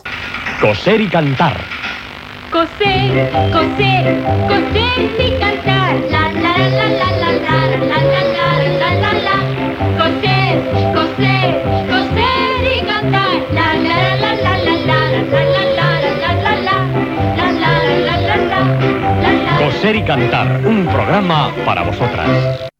Careta d'entrada del concurs.